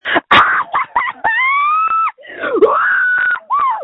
Screams from January 22, 2021
• When you call, we record you making sounds. Hopefully screaming.